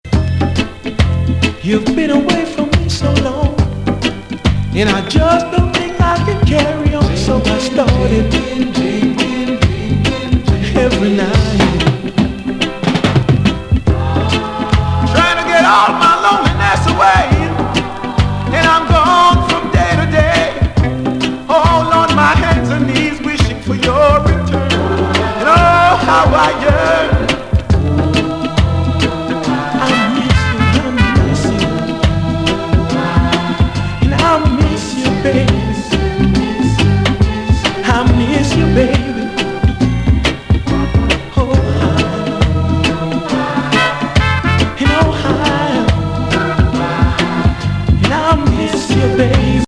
ほっこり素晴らしい
パーカッション&ホーンがいい味。